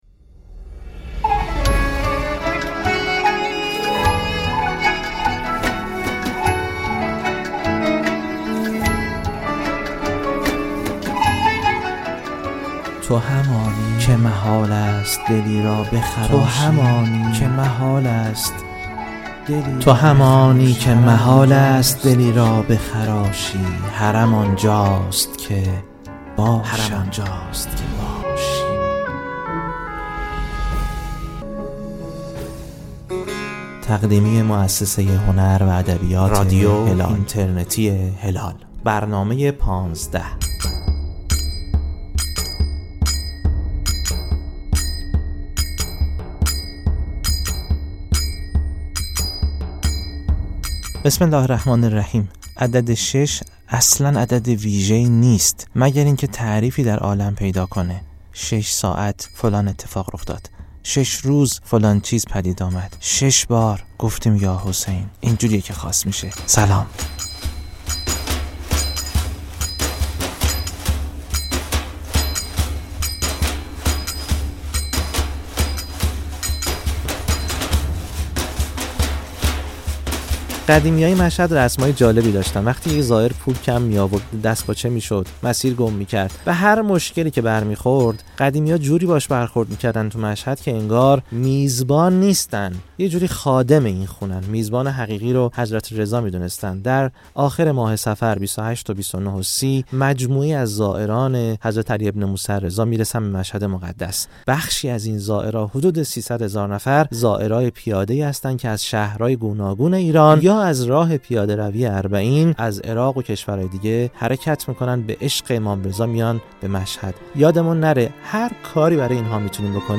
در ششمین قسمت از مجموعه «پانزده»، با نغمه‌هایی دلنشین همراه زائران می‌شویم در لحظات راز و نیاز در مسیر کربلا.